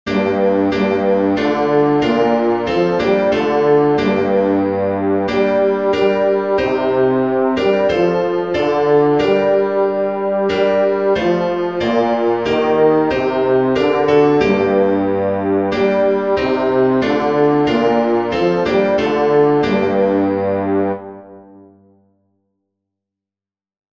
nun_komm_der_heiden_heiland-bass.mp3